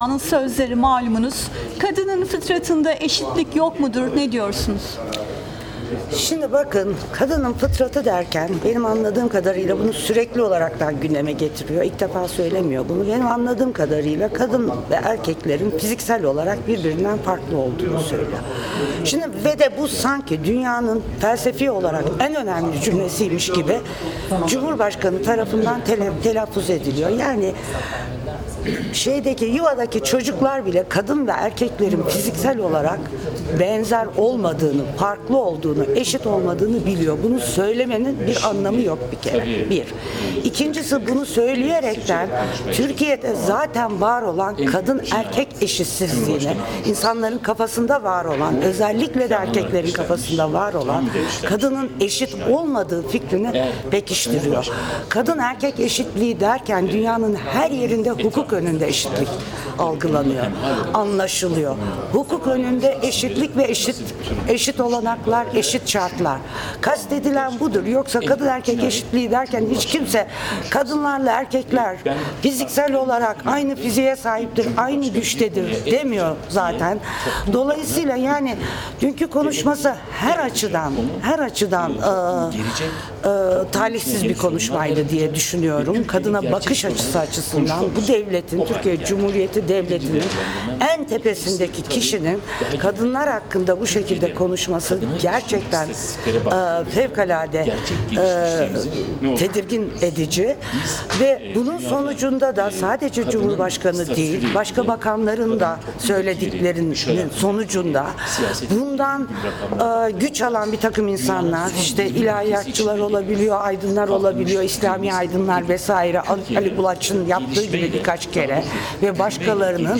CHP İstanbul Milletvekili Binnaz Toprak'ın yorumu